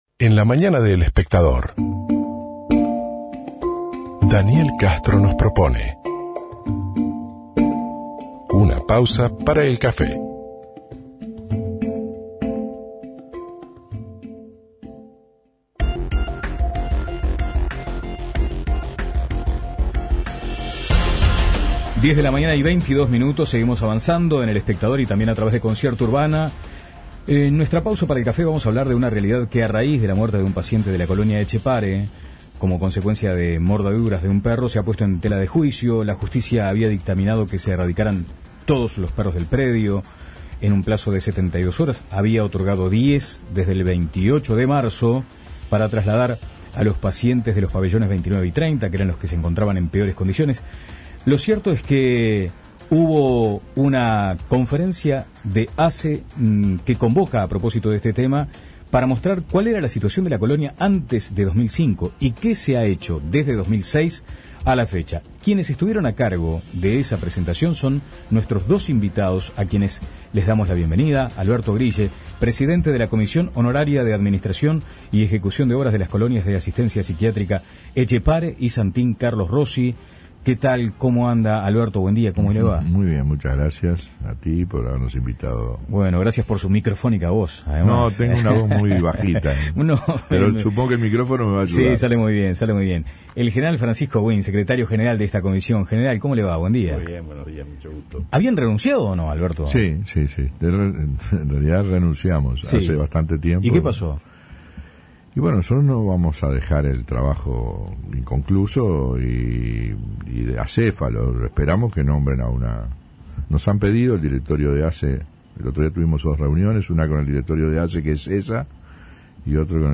Descargar Audio no soportado En nuestra "pausa para el café" recibimos a dos autoridades de ASSE que convocaron a una conferencia sobre el tema, en donde, entre otras cosas, mostraron cuál era la situación de la colonia antes del 2005 y que se ha hecho de 2006 a la fecha.